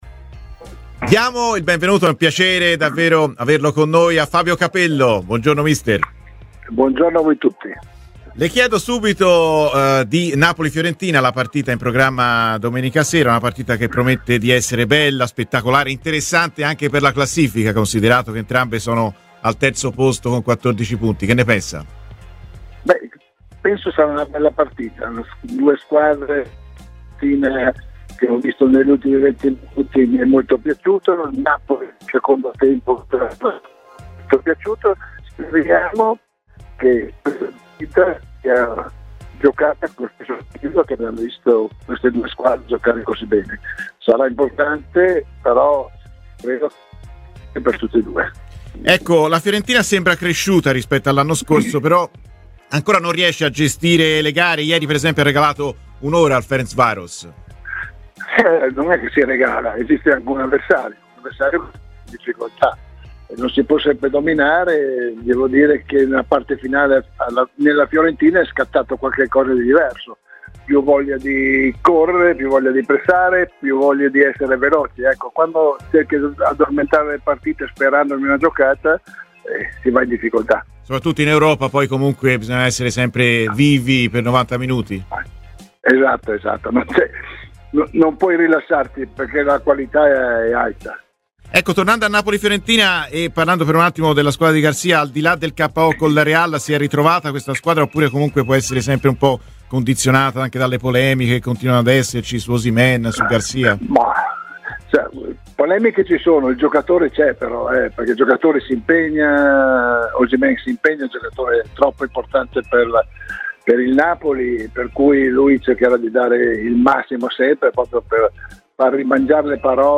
L'ex allenatore di Milan, Roma e Real, Fabio Capello, ha parlato dell'attualità viola a Radio FirenzeViola durante "Viola amore mio": "Penso che contro il Napoli sarà una bella partita. la Fiorentina ieri negli ultimi 20 minuti mi è molto piaciuta così come il Napoli nel secondo tempo Speriamo che le due squadre giochino come sanno giocare".